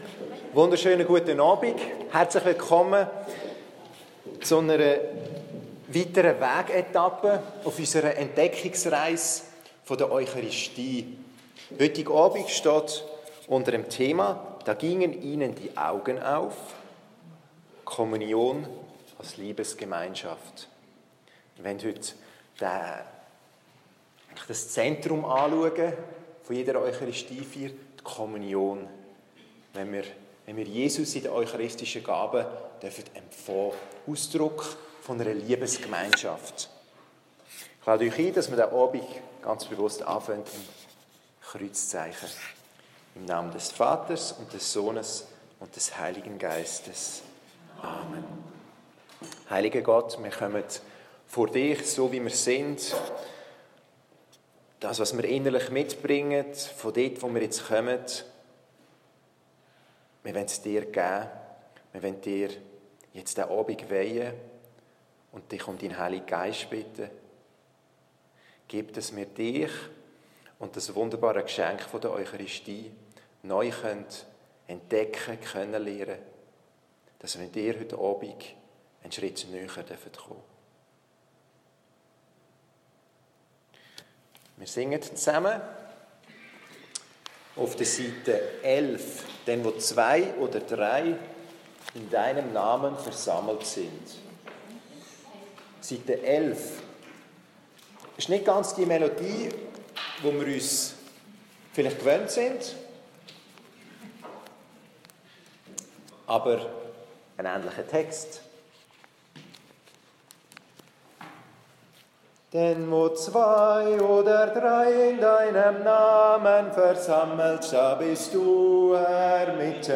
Hier finden Sie einige unserer Glaubenskurse, die live vor Ort aufgezeichnet wurden.